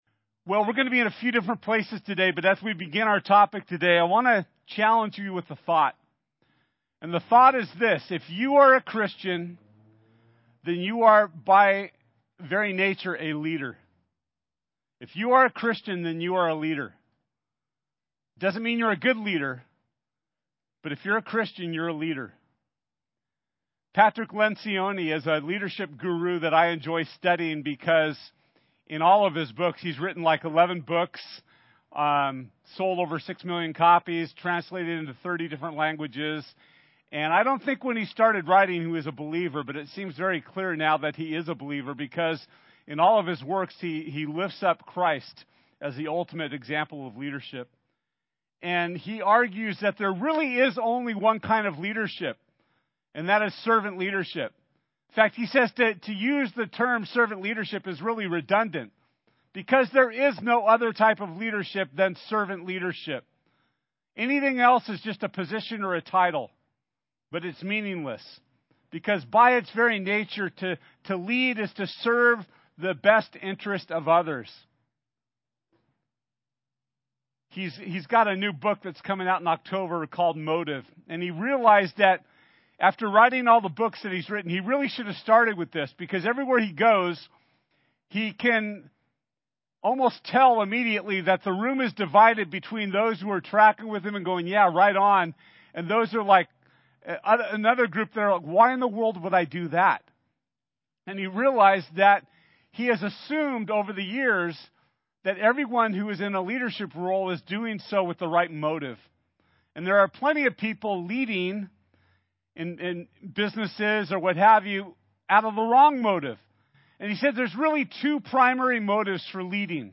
Our family-friendly outdoor service begins at 10 am – so come early to save your spot and to set-up!